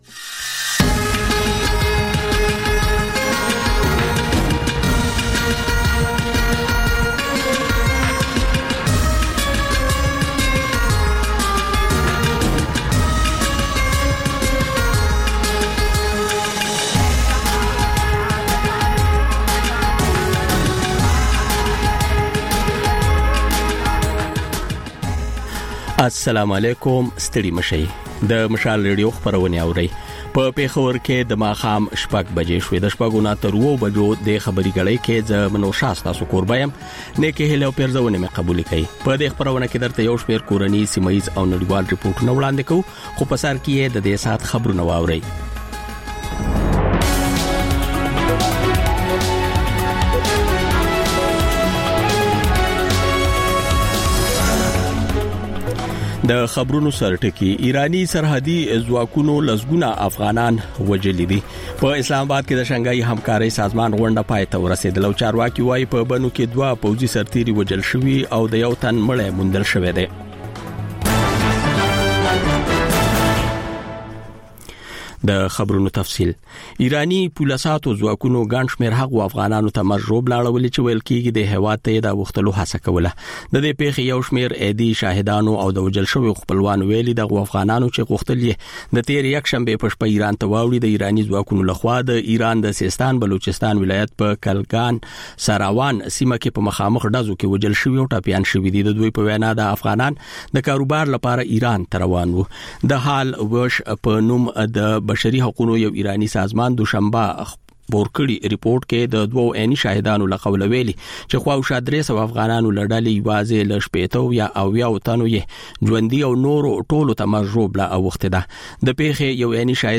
د مشال راډیو د ۱۴ ساعته خپرونو دویمه او وروستۍ خبري ګړۍ. په دې خپرونه کې تر خبرونو وروسته بېلا بېل سیمه ییز او نړیوال رپورټونه، شننې، مرکې، کلتوري او ټولنیز رپورټونه خپرېږي.